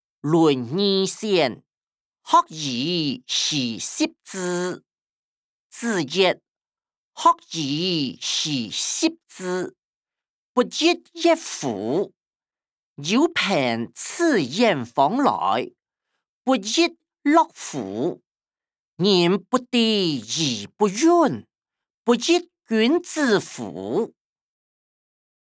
經學、論孟-論語選．學而時習之音檔(大埔腔)